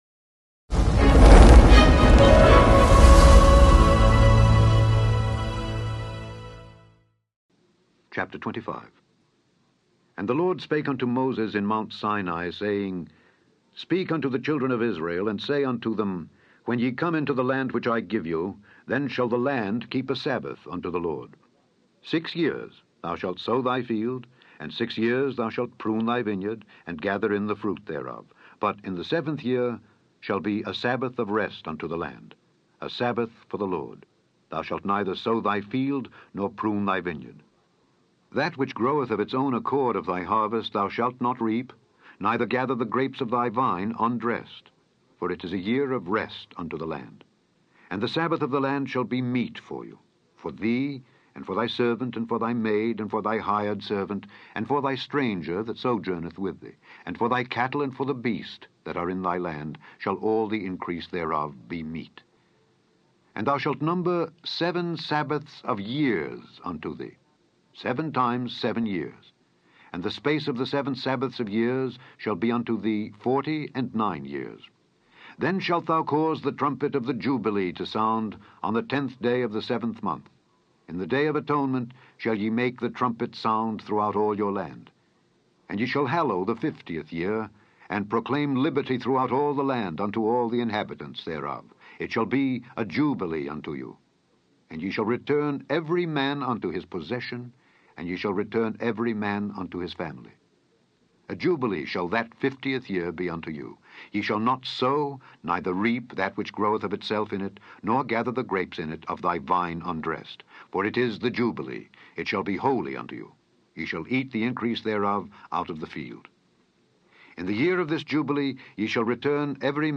In this podcast, you can listen to Alexander Scourby read Leviticus 25-27.